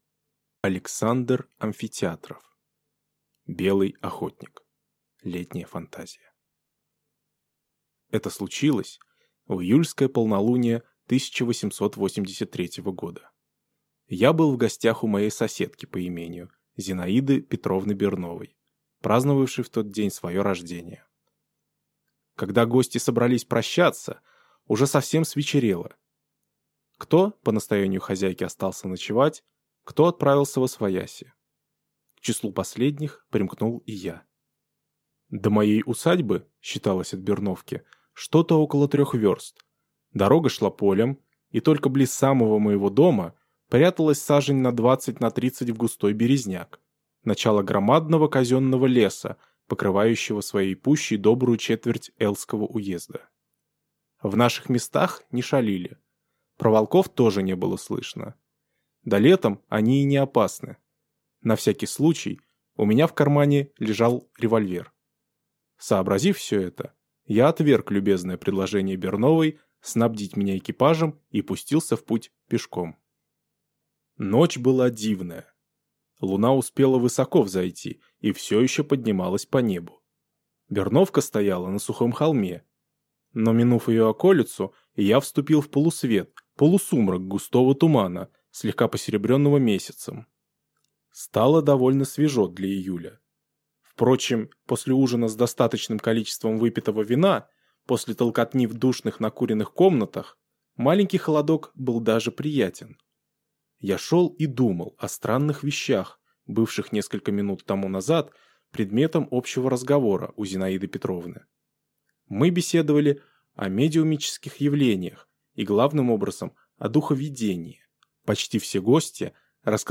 Аудиокнига Белый охотник | Библиотека аудиокниг